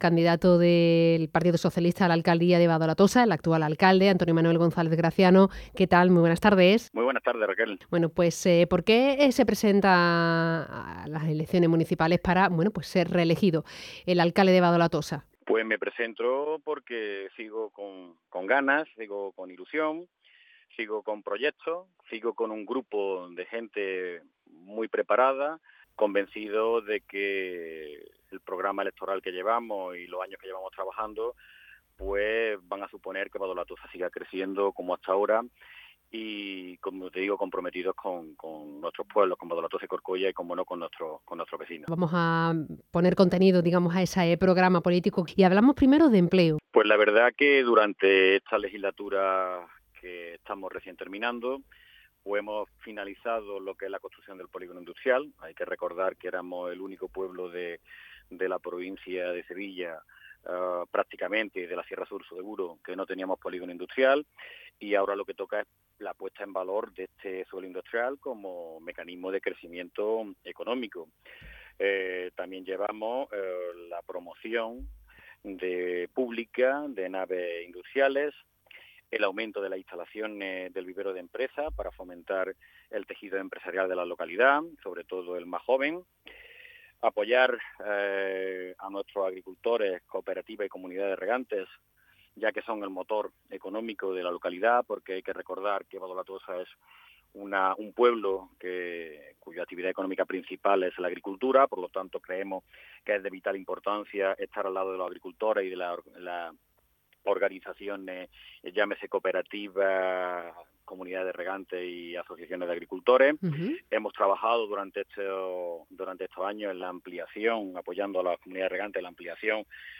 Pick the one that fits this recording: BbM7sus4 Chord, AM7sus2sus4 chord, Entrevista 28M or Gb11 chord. Entrevista 28M